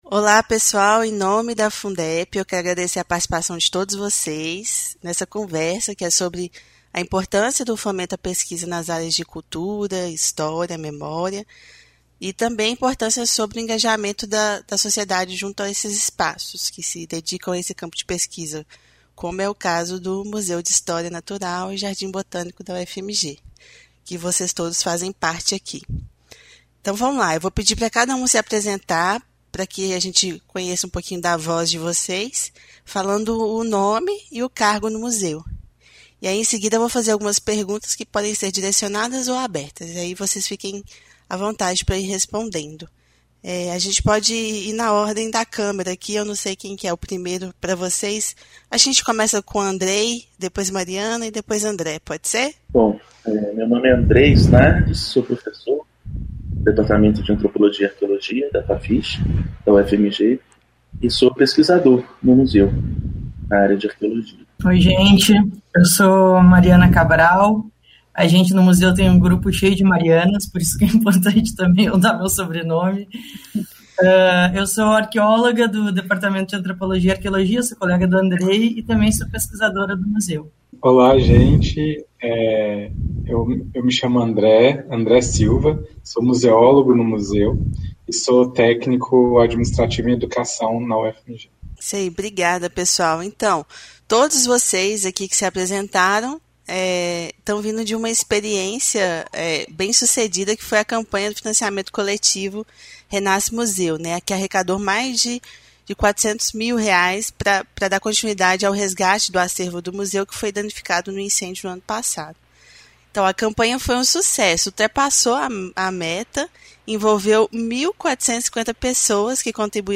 Depois do sucesso do financiamento coletivo para resgatar seu acervo incendiado, Museu de História Natural e Jardim Botânico da UFMG segue fortalecido. Entrevistamos a equipe sobre a importância dos espaços de história e cultura na atualidade.